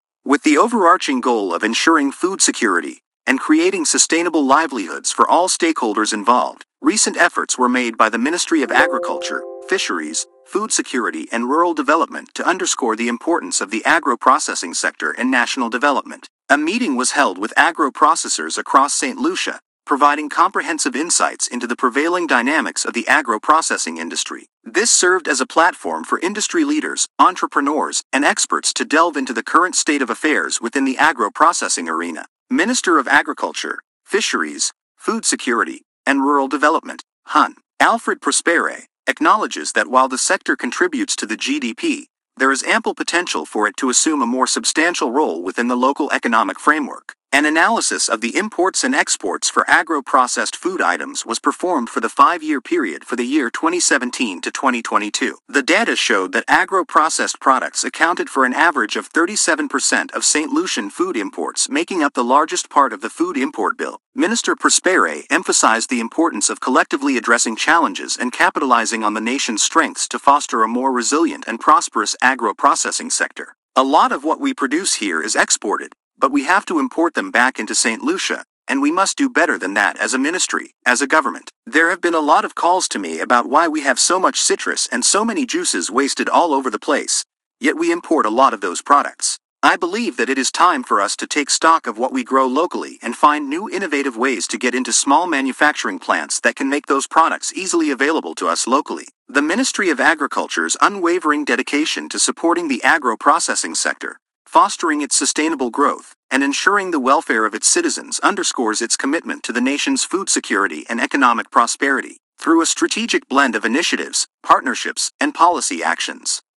Play Press Release